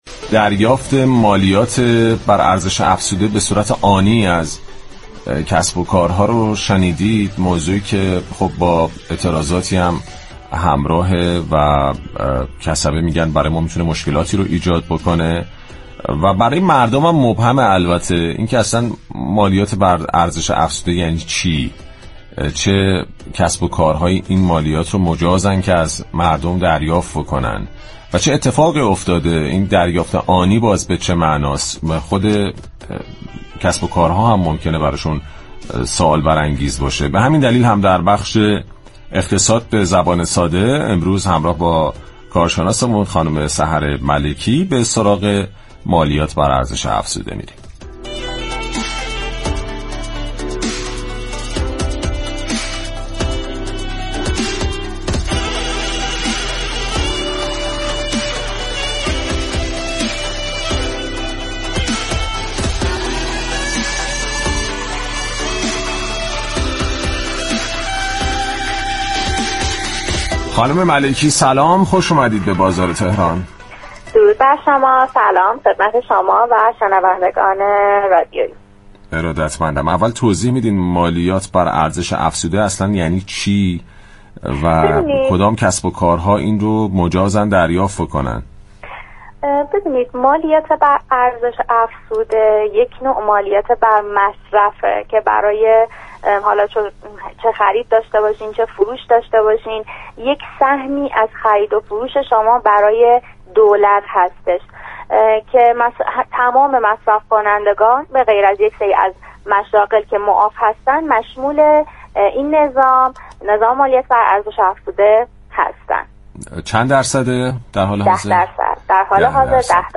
در گفتگو با برنامه «بازار تهران»